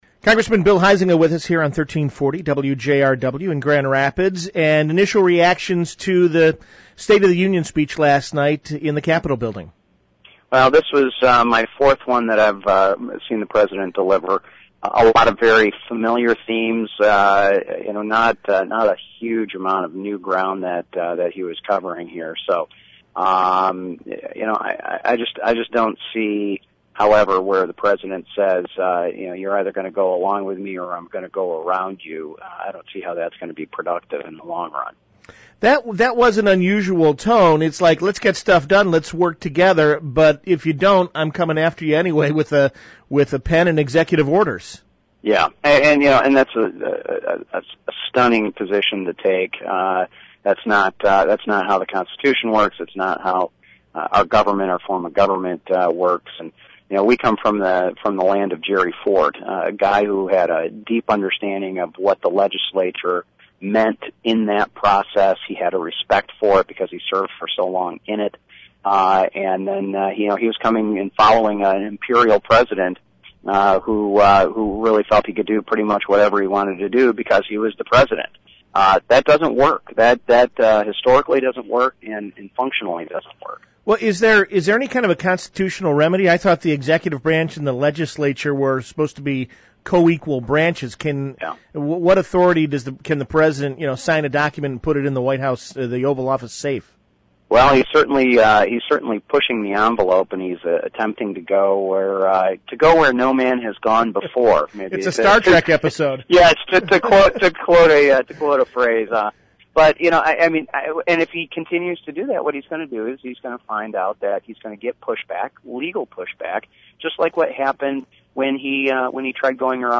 On Wednesday, I spoke with radio shows across Michigan to discuss how President Obama needs to find constitutional, constructive, and respectful ways to work with Congress similar to the approach President Gerald R. Ford took.